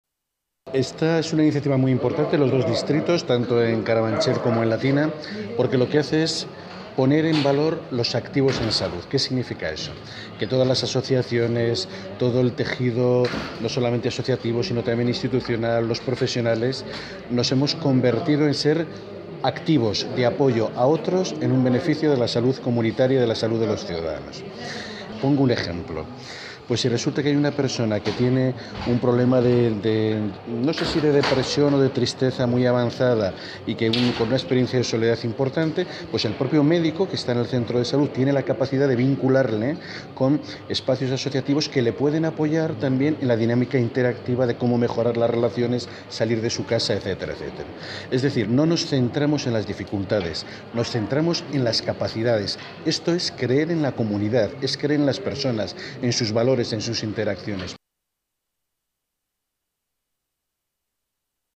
Nueva ventana:Declaraciones de Javier Barbero durante la presentación del proyecto Mapeo